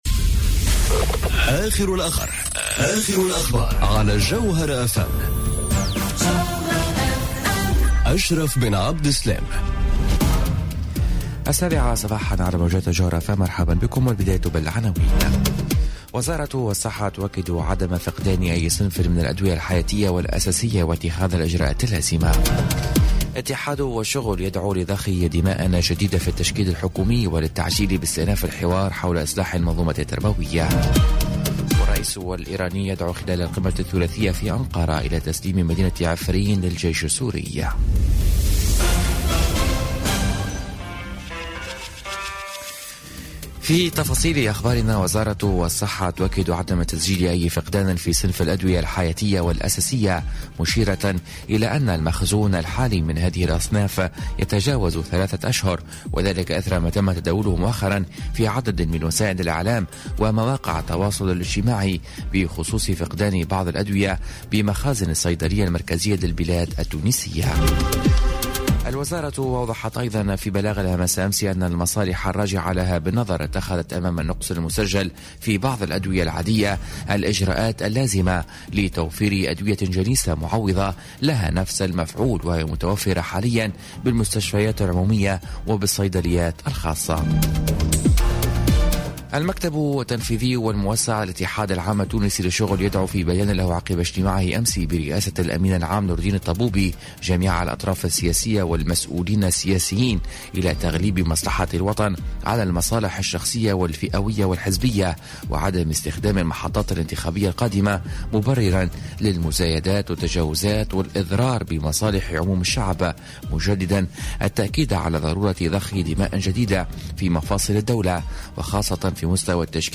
نشرة أخبار السابعة صباحا ليوم الخميس 5 أفريل 2018